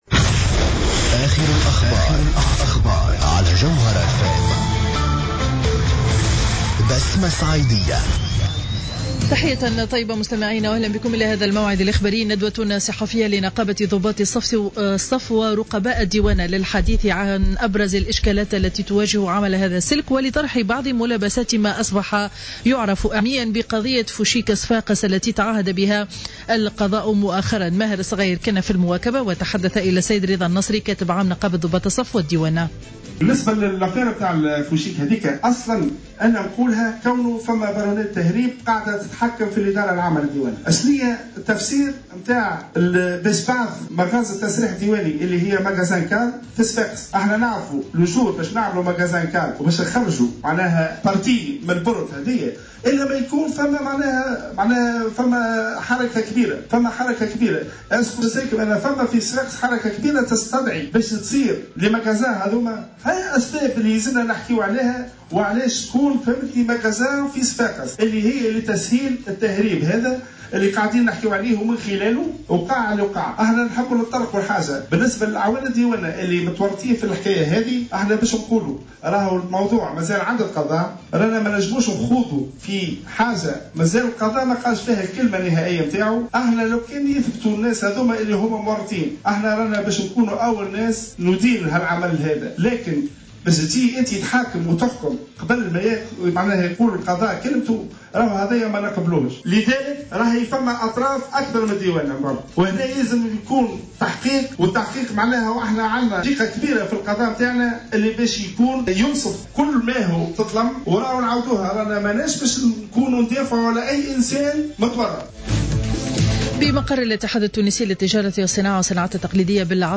نشرة أخبار منتصف النهار ليوم الأربعاء 15 أفريل 2015